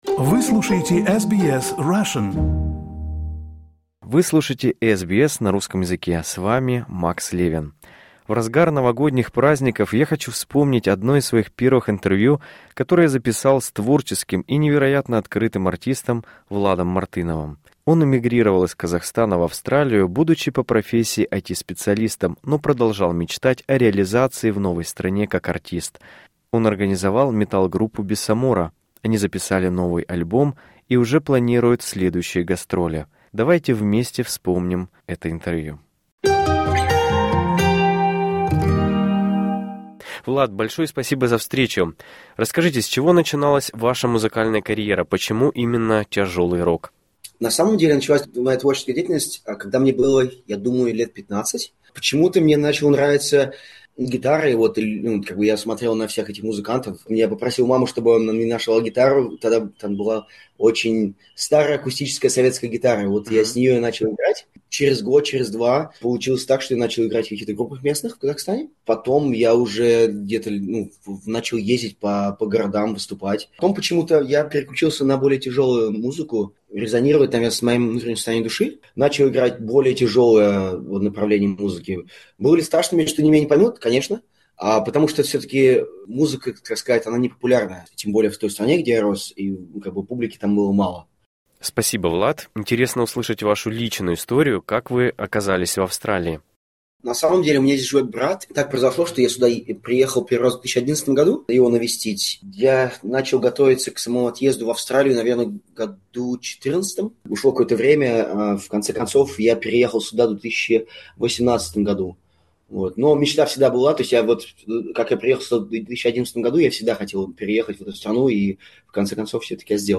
Интервью вышло в августе 2025 года.